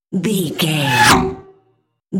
Sci fi vehicle pass by fast
Sound Effects
futuristic
pass by
vehicle